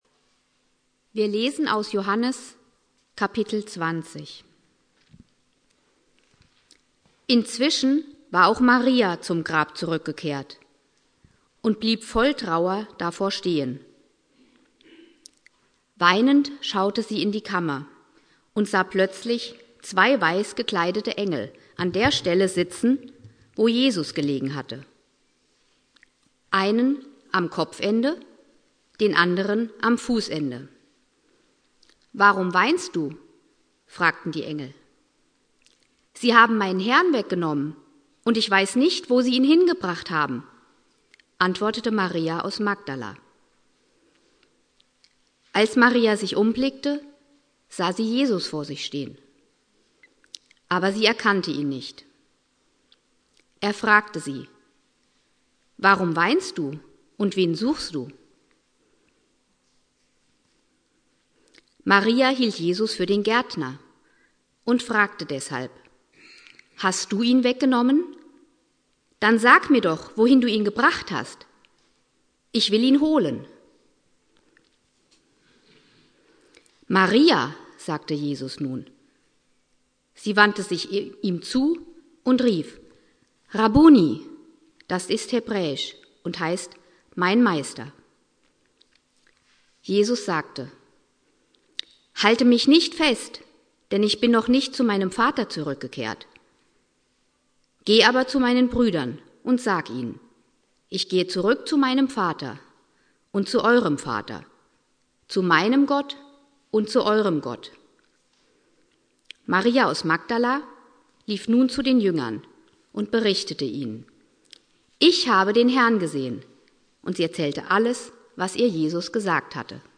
Predigt
Ostersonntag Prediger